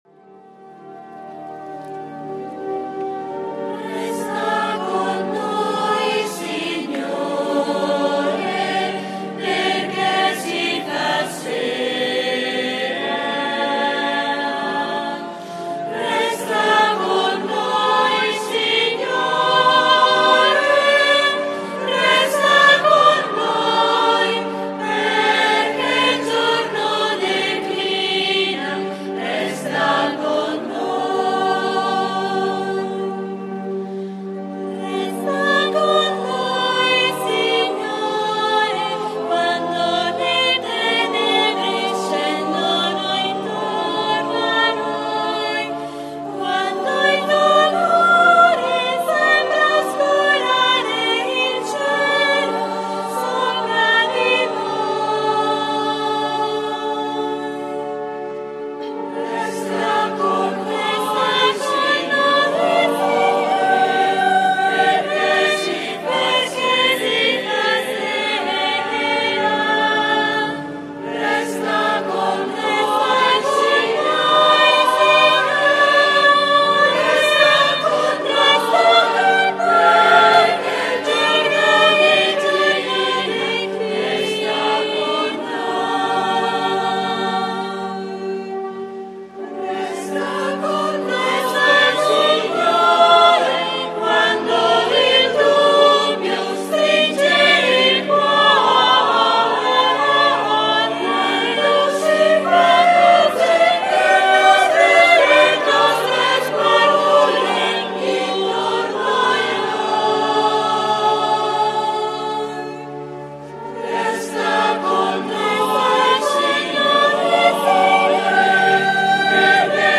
GIOVEDI SANTO -Celebrazione della Cena del Signore